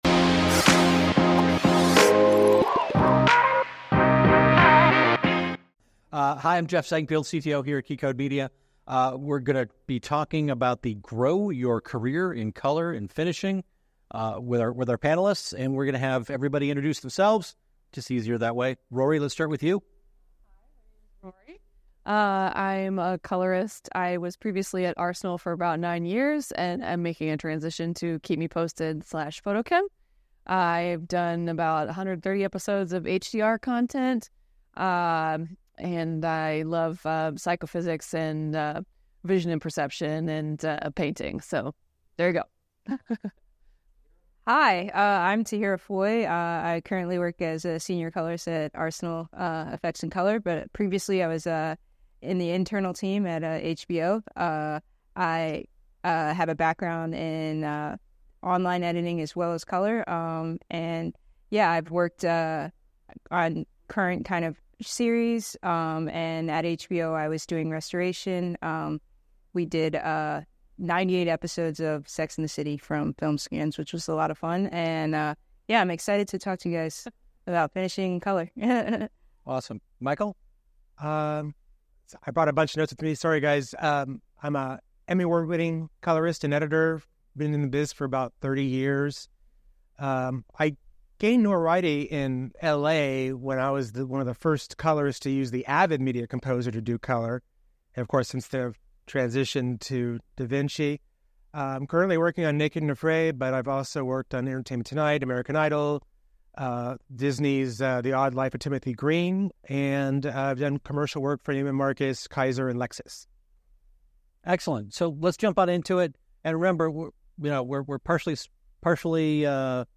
Grow Your Career in Color Grading and Finishing | Panel Discussion